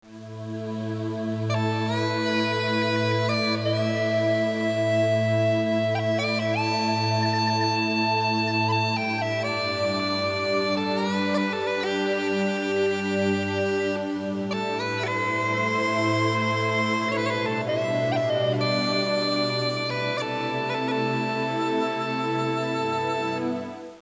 Irish Music
pipe
pipe.wav